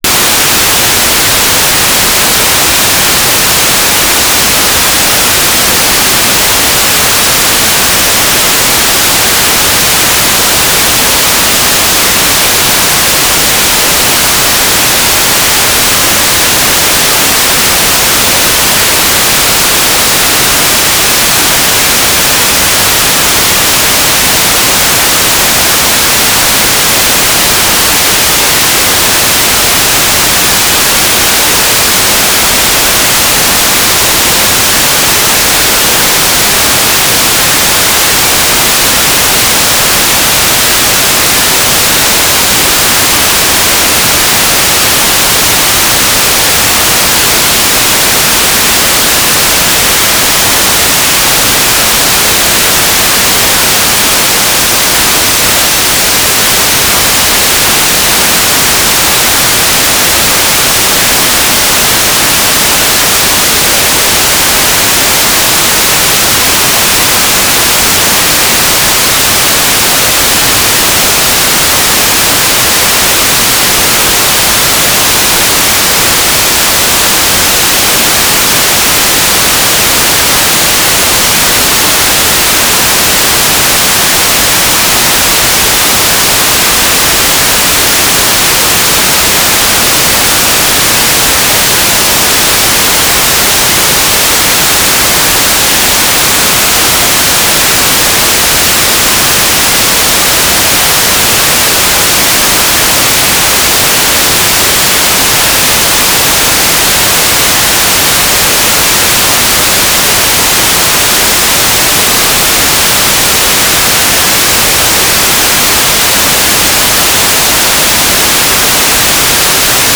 "transmitter_description": "Mode V/U - FM Transceiver",